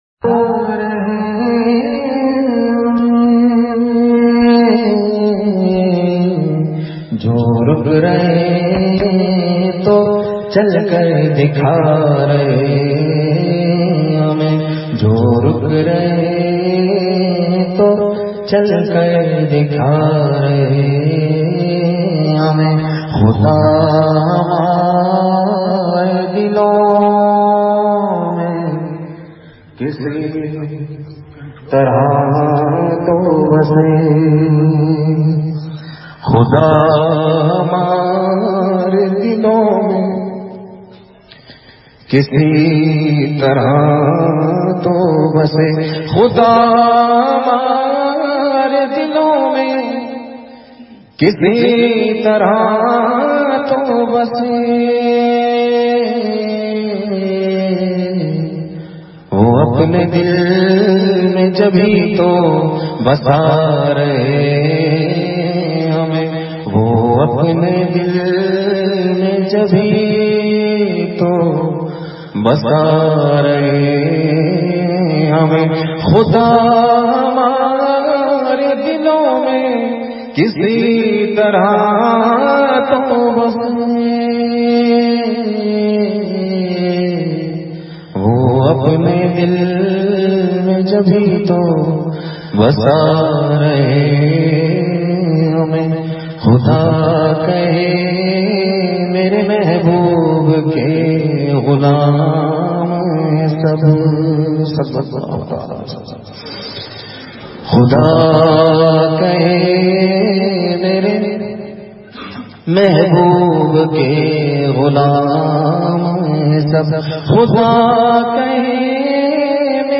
بمقام۔ جامعہ تعلیم القرآن تختہ بند سوات
بہت روتے ہوئے مجلس جس سے انشاء اللہ دل کی دنیا بدل جائے گی ۔